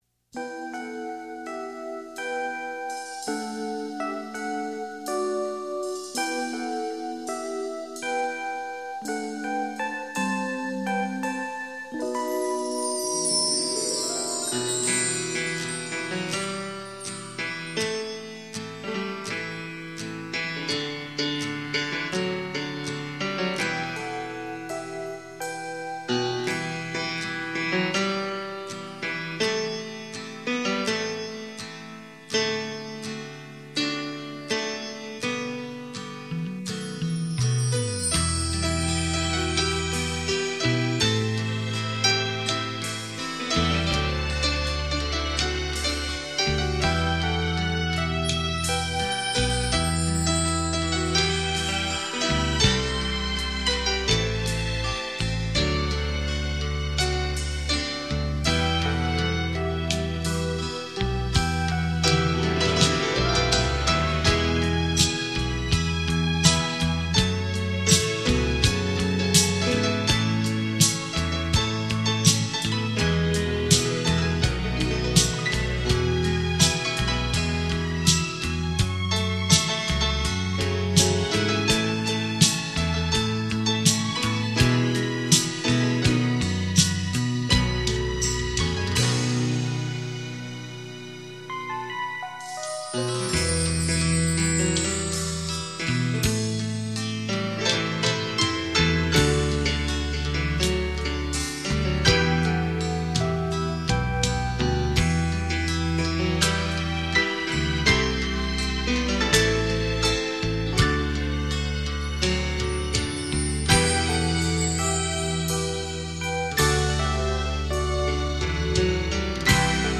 经典演奏曲
感受独特魅力，音乐的神奇，还有双钢琴的极致艺术。
双钢琴的震撼，与您体验琴乐声交织而成的文化艺术飨宴。